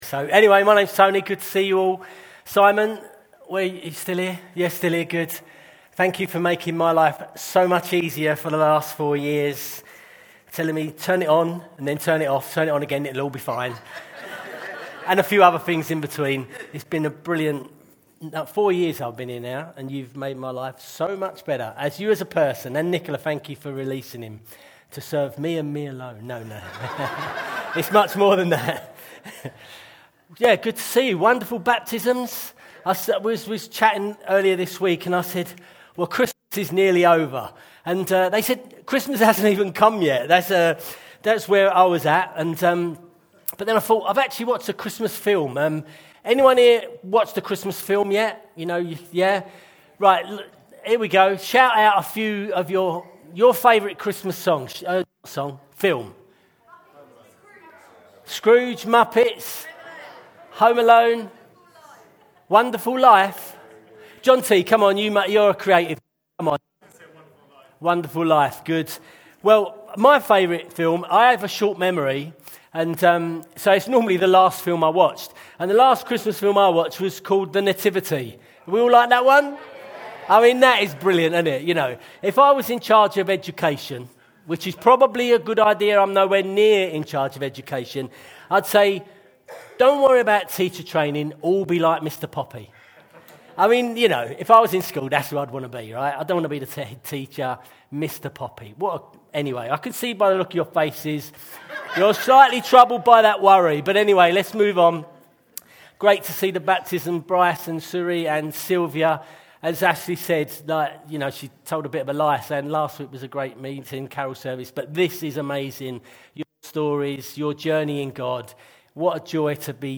Sunday Teaching The Magnificat (Chichester)